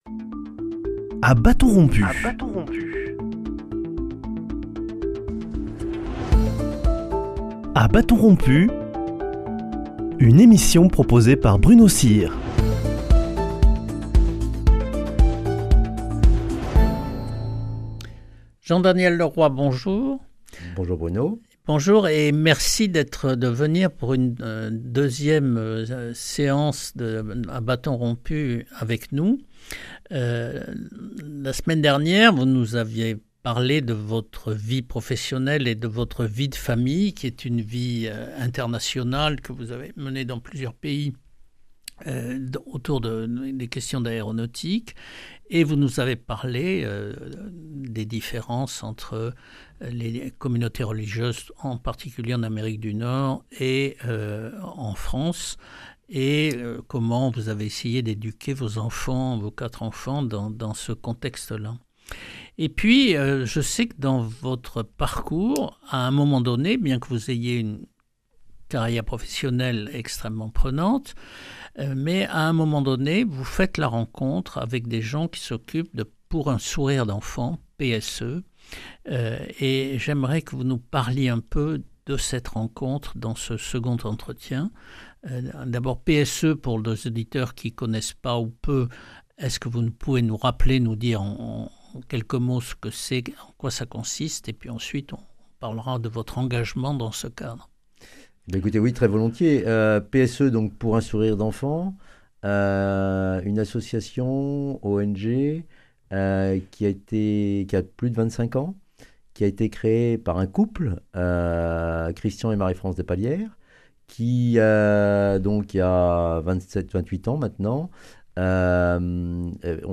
Dans le dernier entretien qu’il nous a accordé, il explique comment on peut soutenir cette association. Il nous parle aussi du Cambodge aujourd’hui et du rôle des ONG dans les pays en voie de développement.